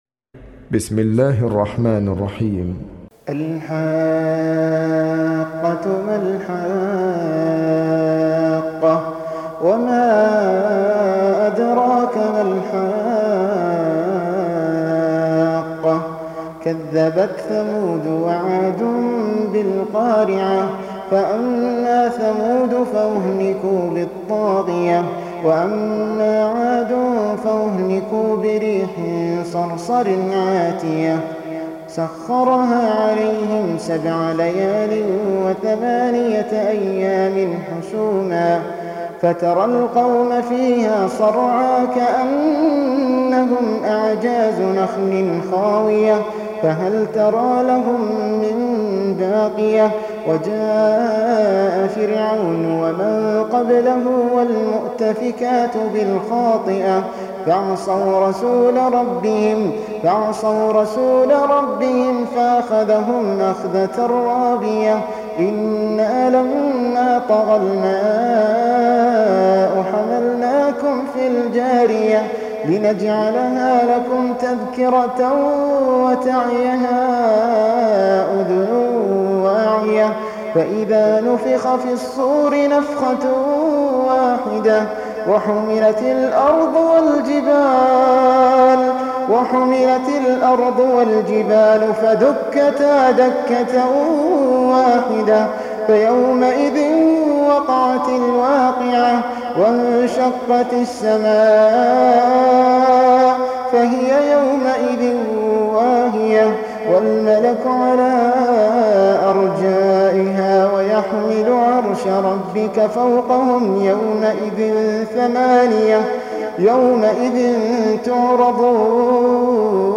Surah Sequence تتابع السورة Download Surah حمّل السورة Reciting Murattalah Audio for 69. Surah Al-H�qqah سورة الحاقة N.B *Surah Includes Al-Basmalah Reciters Sequents تتابع التلاوات Reciters Repeats تكرار التلاوات